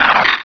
pokeemerald / sound / direct_sound_samples / cries / zangoose.aif
zangoose.aif